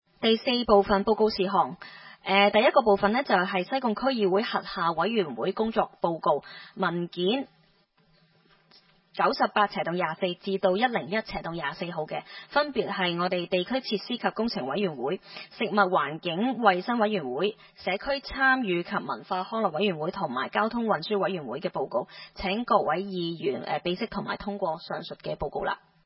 區議會大會的錄音記錄
西貢將軍澳政府綜合大樓三樓